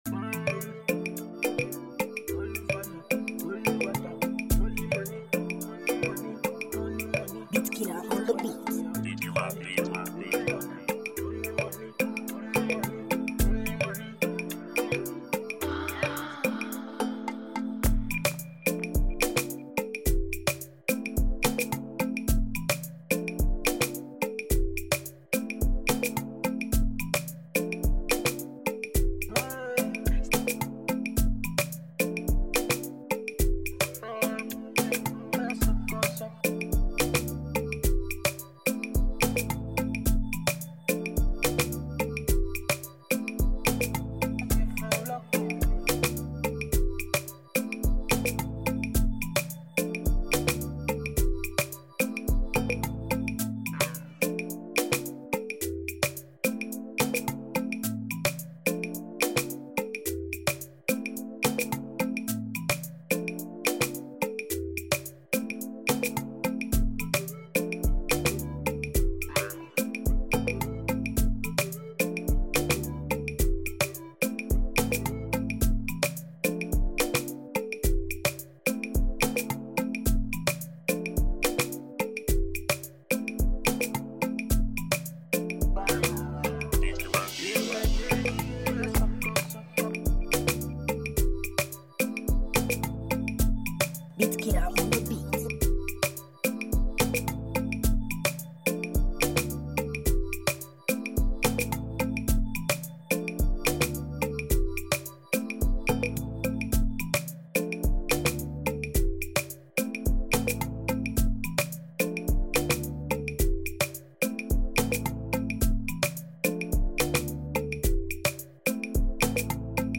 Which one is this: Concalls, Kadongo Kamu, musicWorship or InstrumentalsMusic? InstrumentalsMusic